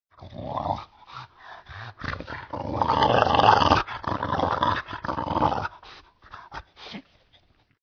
Недовольство юного бульдога проявляется в таком звуке (ему 18 месяцев)